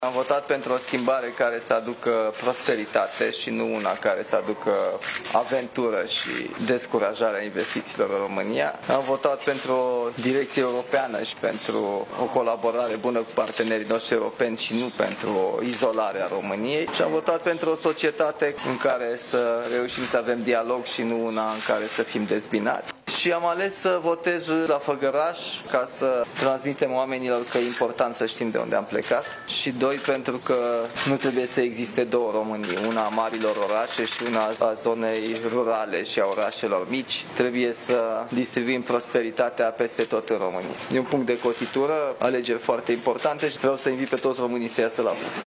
La ieșirea de la urne, Nicușor Dan a declarat că a votat pentru pentru o direcție europeană și pentru o colaborare bună cu partenerii europeni ai României.